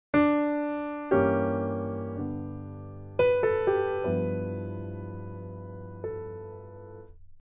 Root-diminished
An interesting way of using a diminished chord is to replace an existing chord with a diminished chord built on the root of that chord.
This method works great when you then resolve back to the original chord.